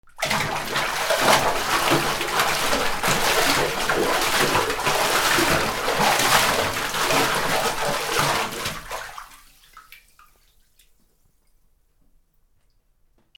水の中で暴れる 2
『バシャバシャ』